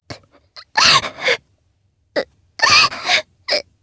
Sonia-Vox_Sad_jp.wav